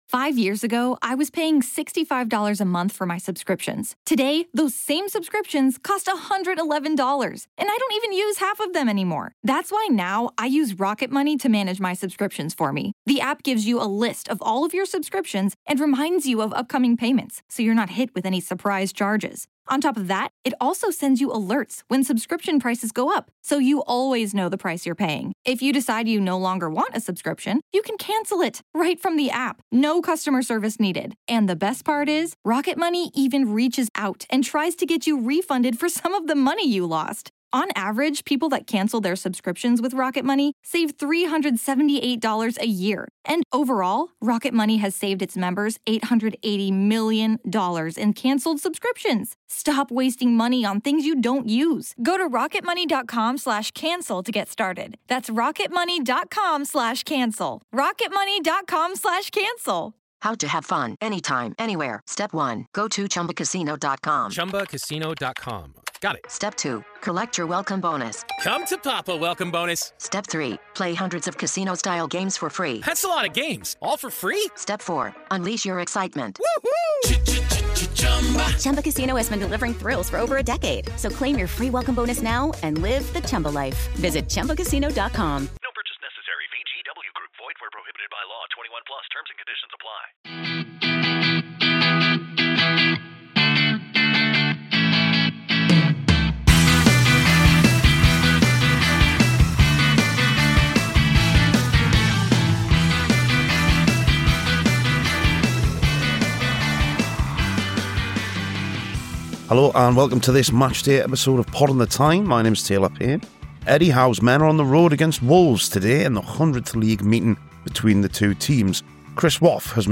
Honestly; listen to the podcast by all means, there's a discussion about strikers and away form and you can hear from Eddie Howe as well, but the fact you're still reading this is a cause for concern.